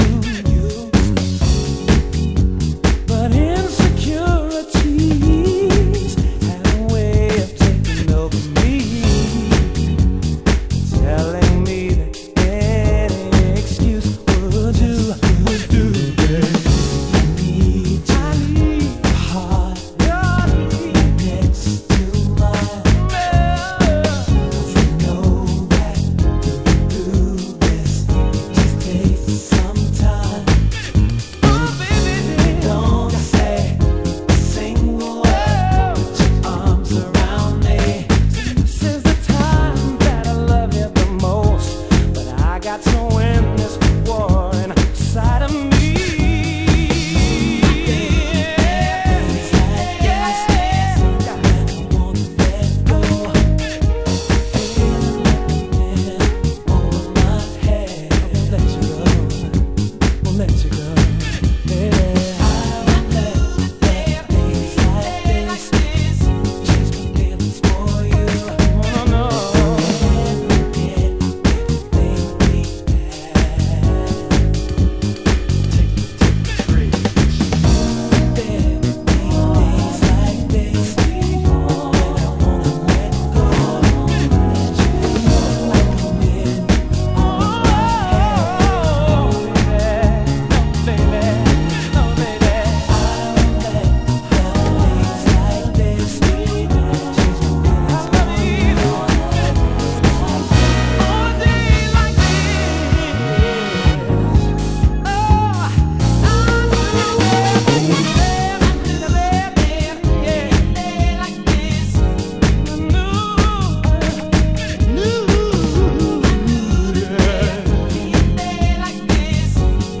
ハウス専門店KENTRECORD（ケントレコード）
盤質：少しチリパチノイズ有/ラベルにシミ汚れ有